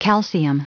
Prononciation du mot calcium en anglais (fichier audio)
Prononciation du mot : calcium